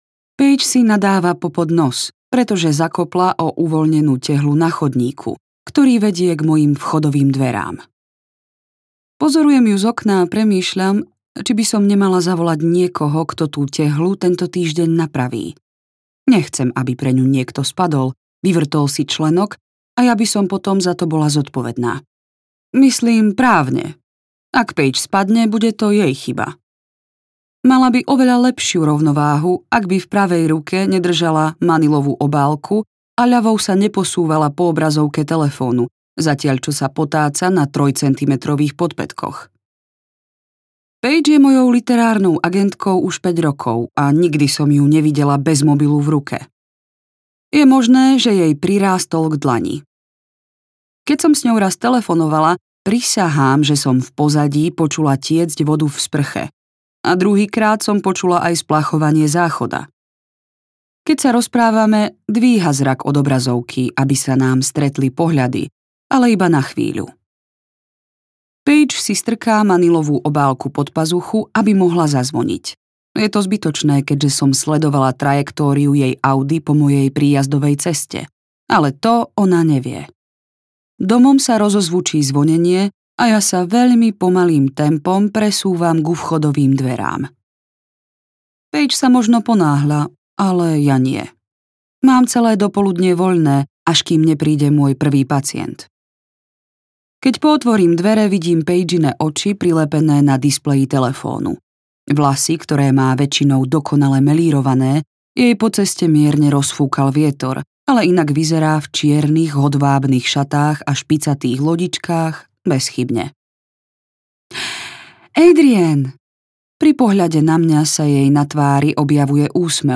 Anatómia lží audiokniha
Ukázka z knihy